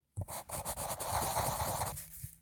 menu-exit-click.ogg